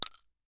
Bullet Shell Sounds
shotgun_generic_1.ogg